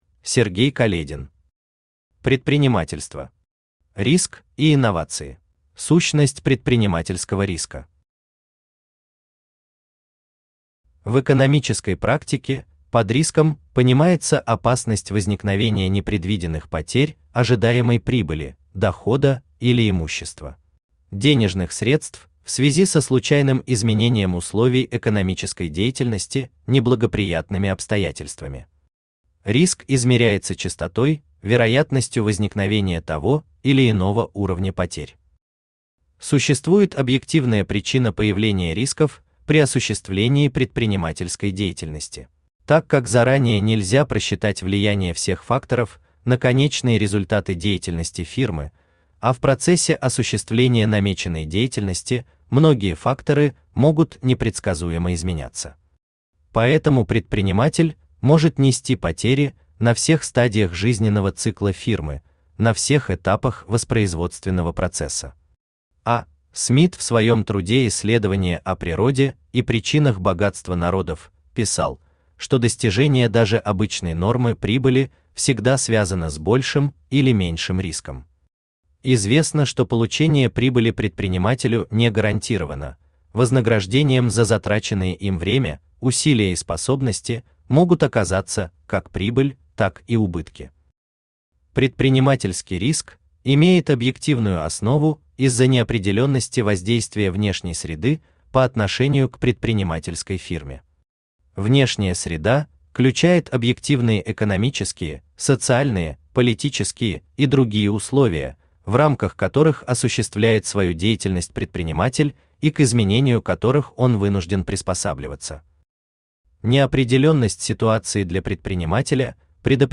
Аудиокнига Предпринимательство. Риск и инновации | Библиотека аудиокниг
Aудиокнига Предпринимательство. Риск и инновации Автор Сергей Каледин Читает аудиокнигу Авточтец ЛитРес.